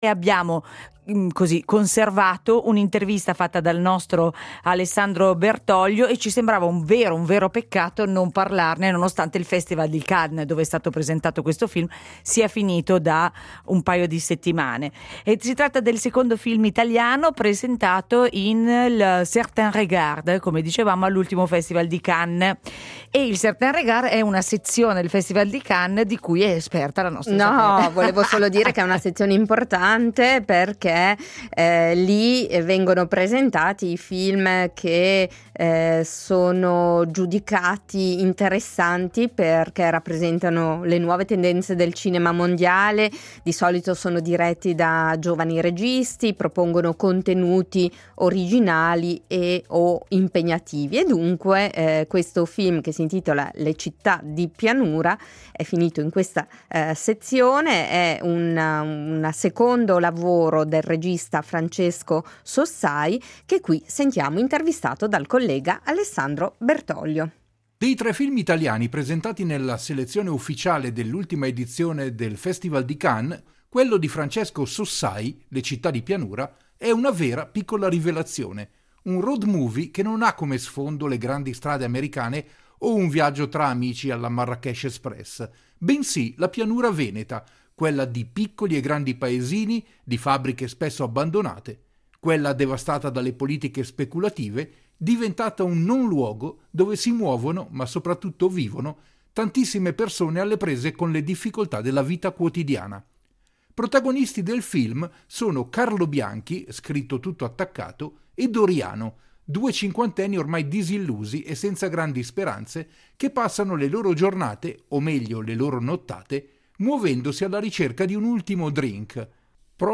Un’intervista